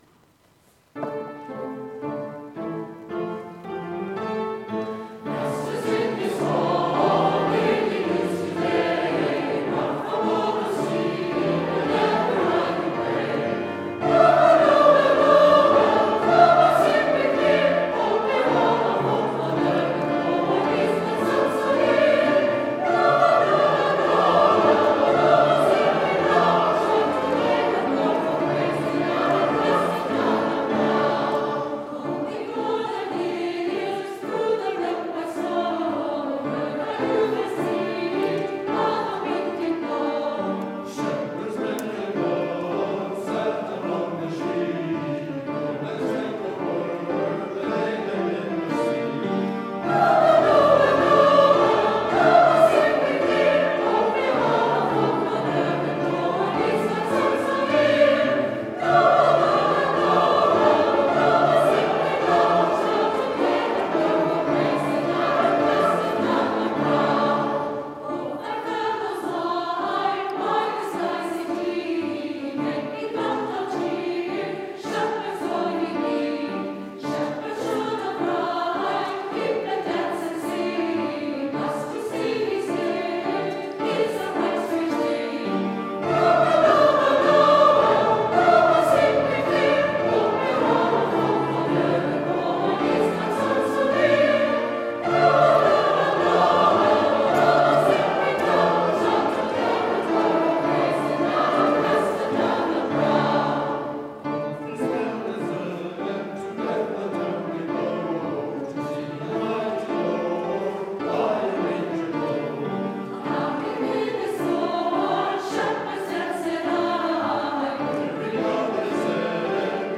December 1, 2024 - St. Catherine's Choirs Christmas Concert
----- Diocesan Choir -----